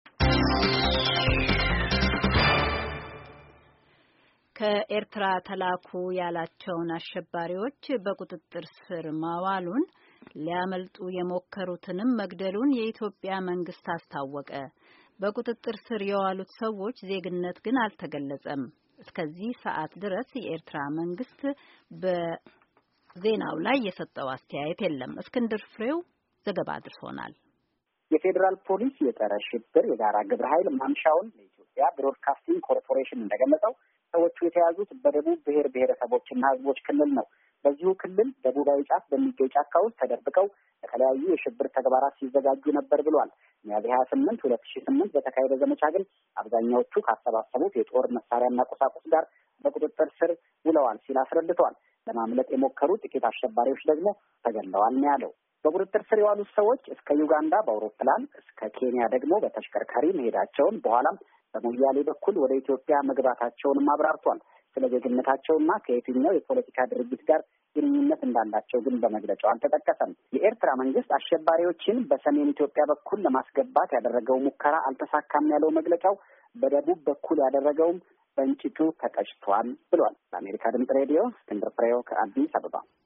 አጭር ዘገባ